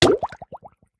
Minecraft Version Minecraft Version 1.21.5 Latest Release | Latest Snapshot 1.21.5 / assets / minecraft / sounds / entity / player / hurt / drown1.ogg Compare With Compare With Latest Release | Latest Snapshot
drown1.ogg